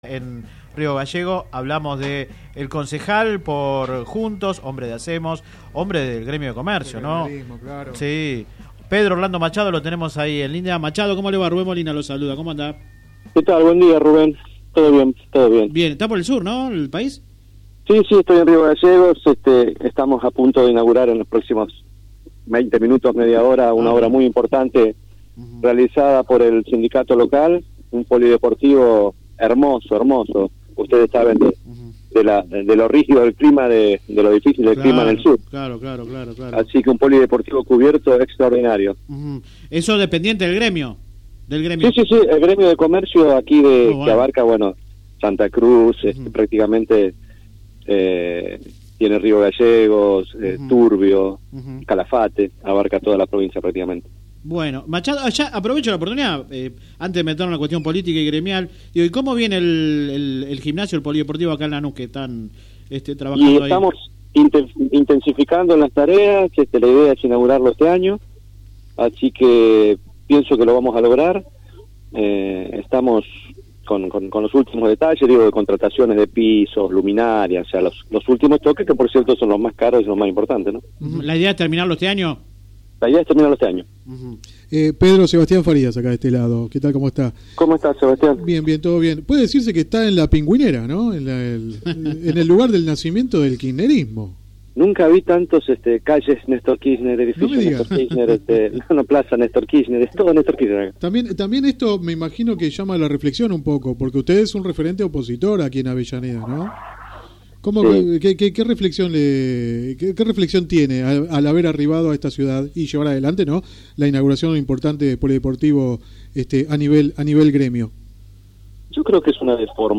El precandidato a intendente por Juntos habló en el programa radial Sin Retorno (lunes a viernes de 10 a 13 por GPS El Camino FM 90 .7 y AM 1260). Durante la entrevista criticó la gestión municipal y habló sobre su futuro.
Click acá entrevista radial